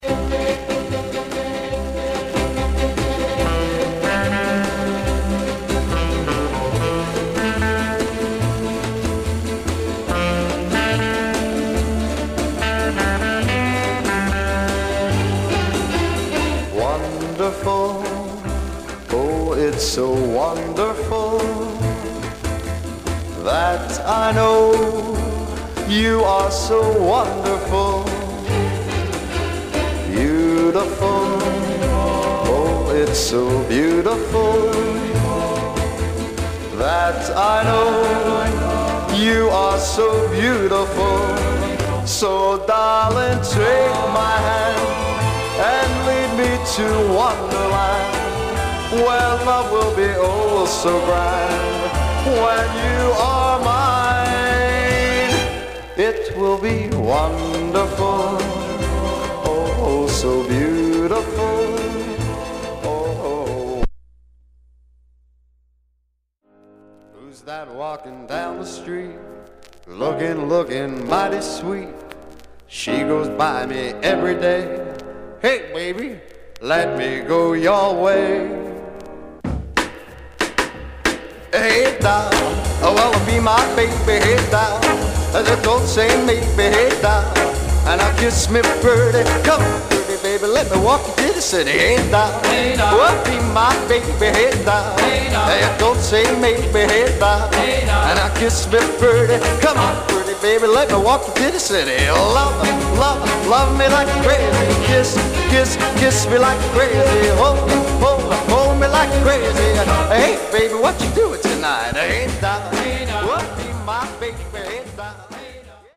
Condition Some surface noise/wear Stereo/mono Mono
Teen